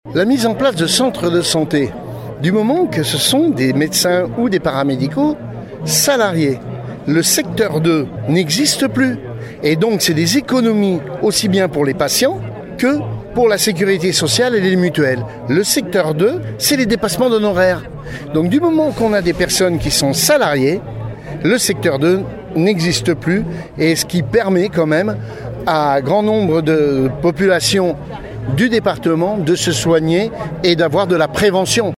Une cinquantaine de personnes mobilisées à l’appel de la CGT sur le parvis du Conseil départemental de la Charente-Maritime à La Rochelle.